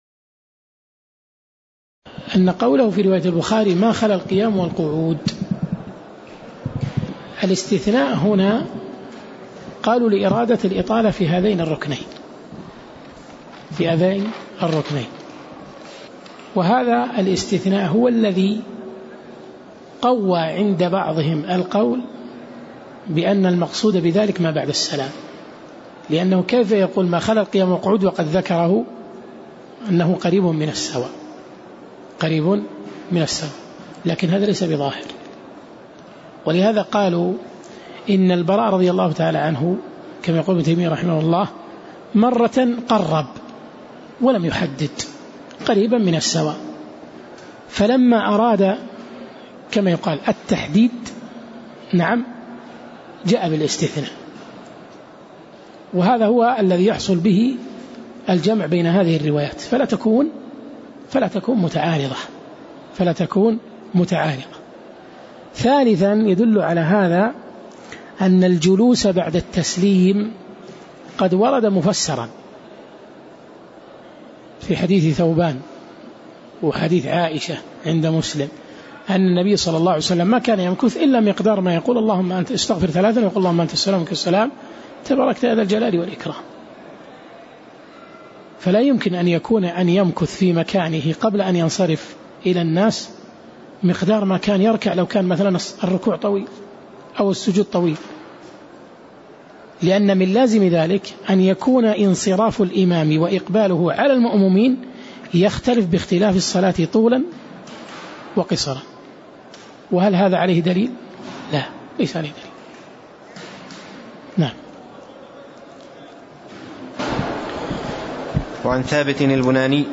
تاريخ النشر ١٣ شعبان ١٤٣٦ هـ المكان: المسجد النبوي الشيخ